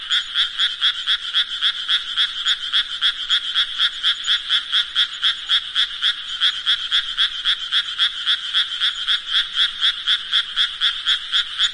自然界 " 青蛙
标签： 日本 稻田 爬行动物 青蛙 日本 性质 现场录音
声道立体声